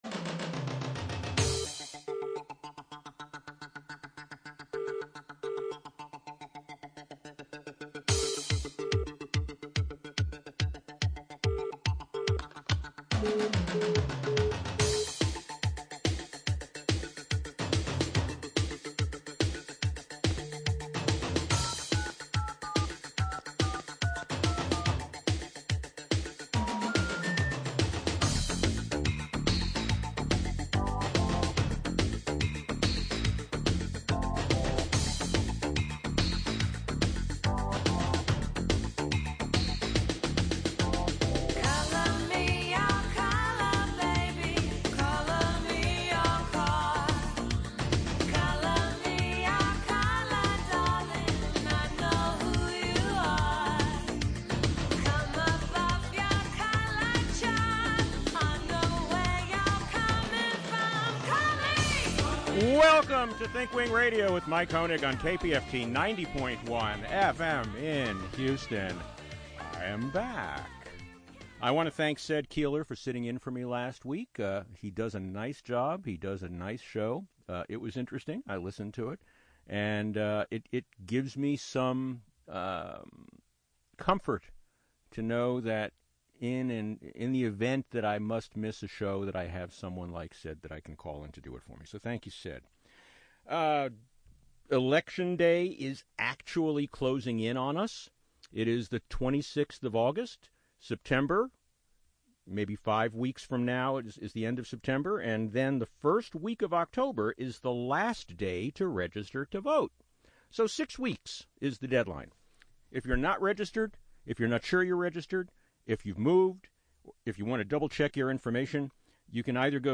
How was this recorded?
Today’s show is a fundraising show, so, with apologies, we can’t take on-air phone calls, Listen live on the radio, or on the internet from anywhere in the world!